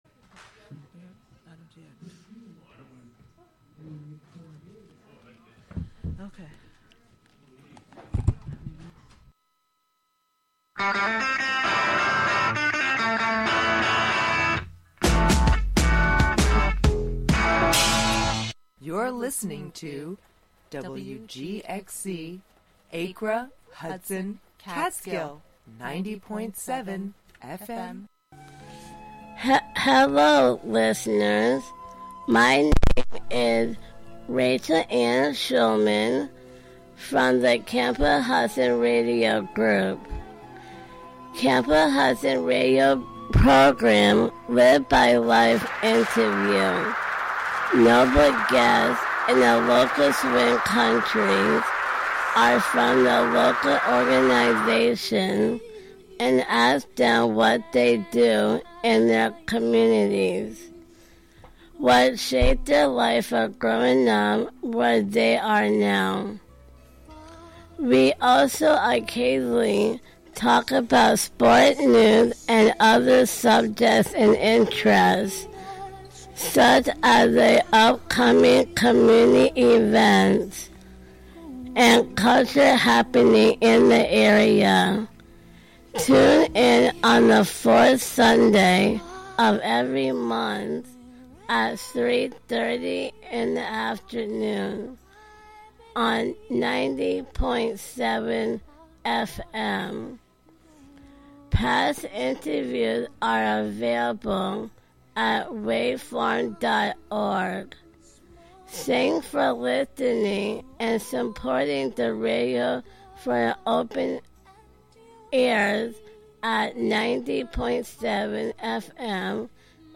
Hosted by various WGXC Volunteer Programmers.